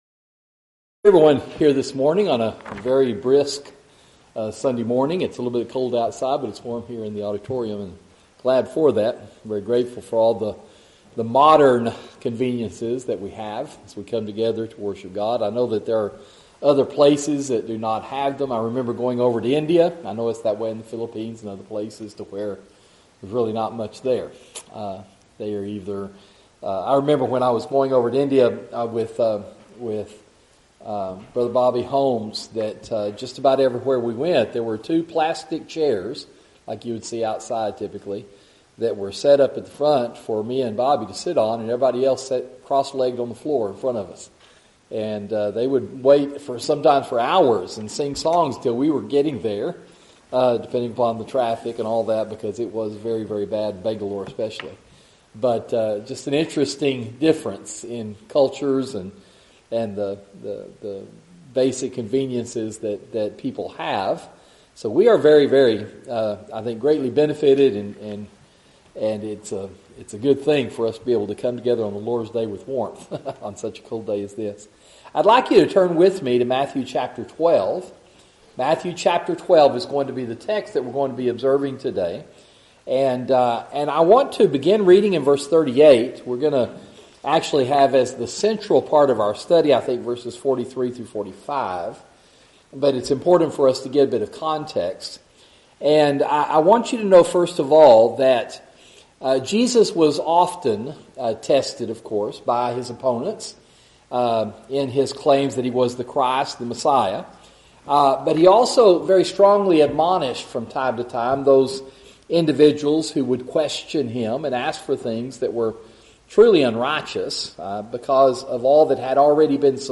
Audio YouTube Video of Sermon Share this: Tweet